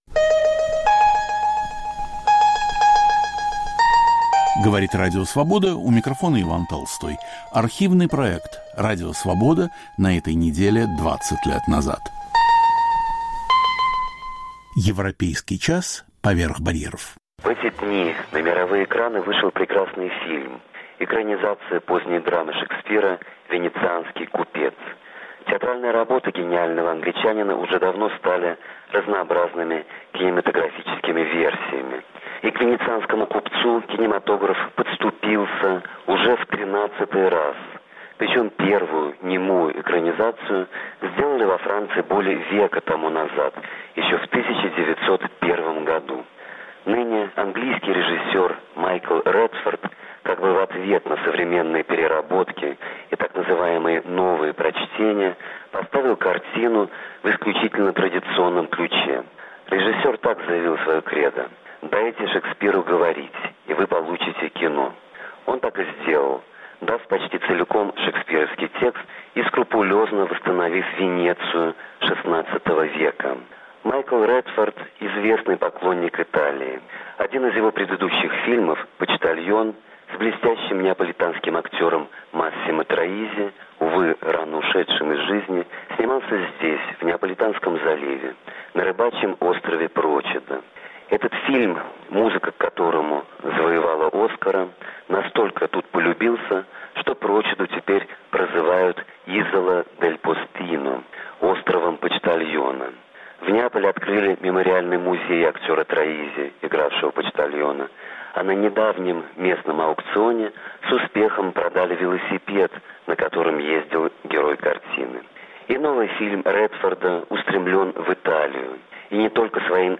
Редактор и ведущий Иван Толстой.